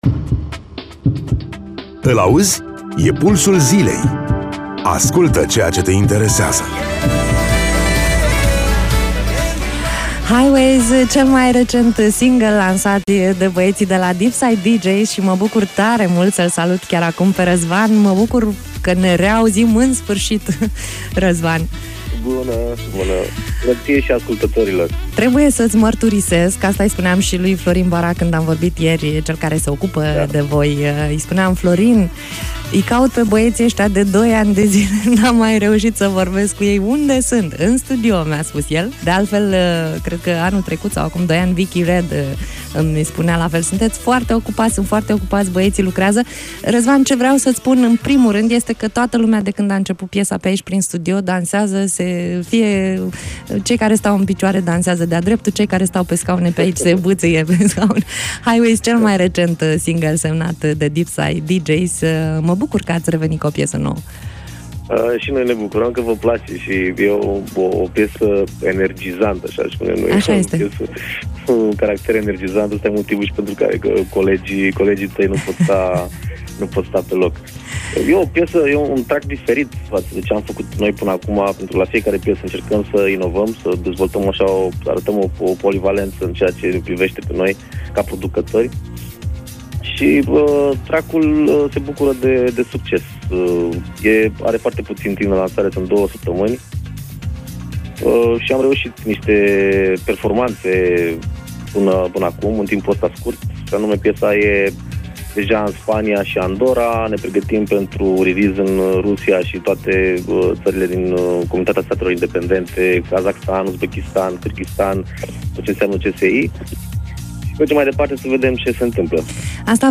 06-Nov-Interviu-Deepside-Deejays.mp3